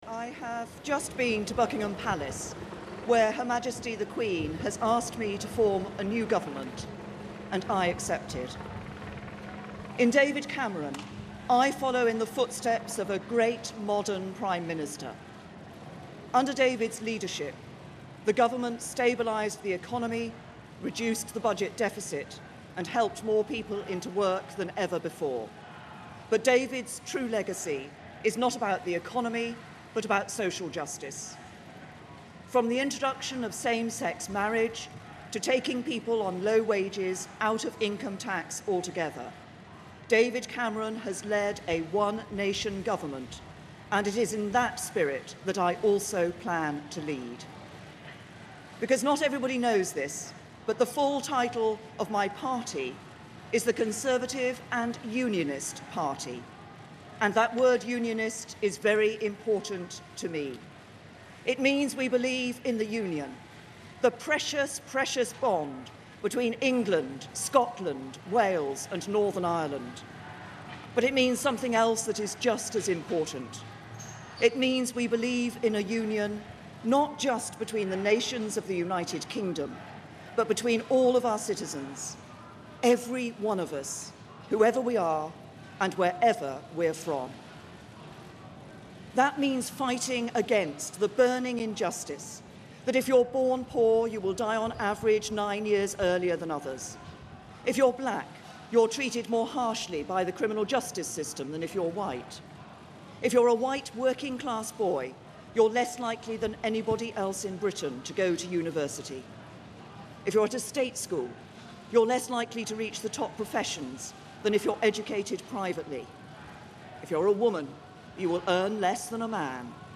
Theresa May has given her first speech as Prime Minister